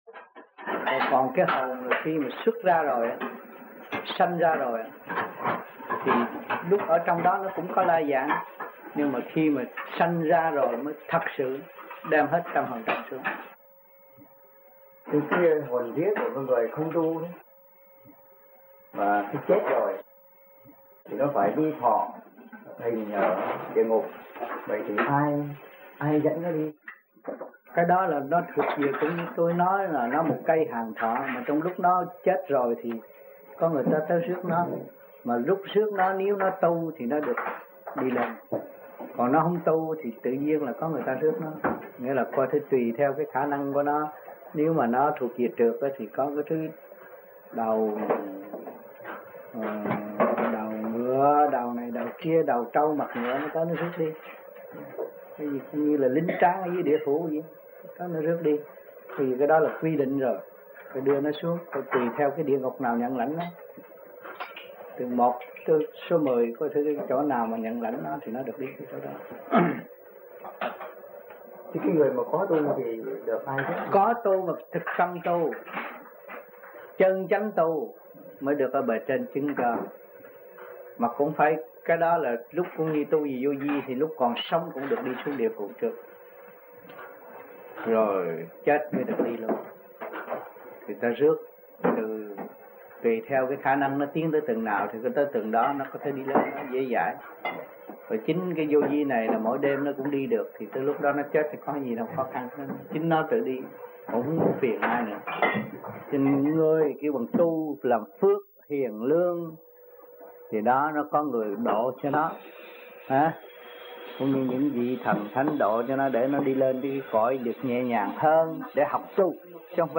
1980-01-19 - Montreal - Thuyết Pháp 1